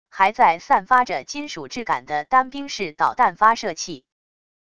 还在散发着金属质感的单兵式导弹发射器wav音频